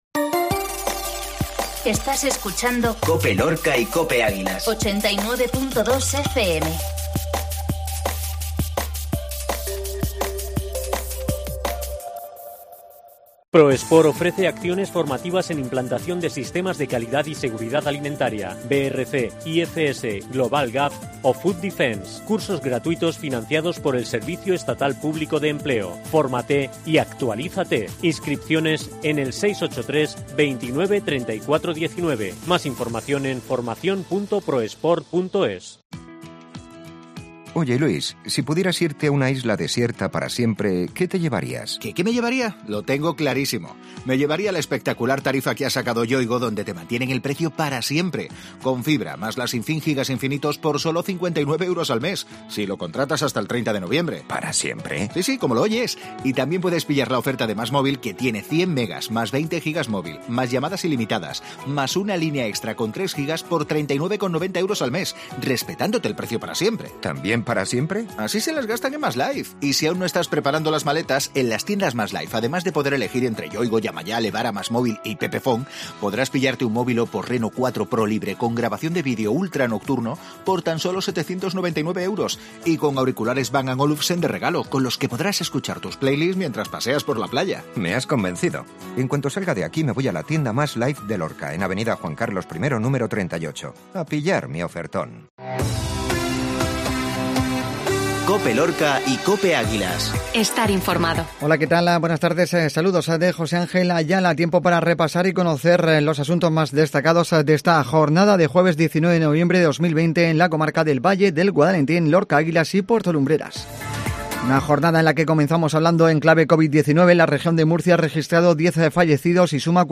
INFORMATIVO MEDIODÍA COPE LORCA